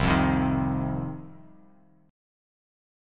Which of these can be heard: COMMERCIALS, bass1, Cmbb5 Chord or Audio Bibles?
Cmbb5 Chord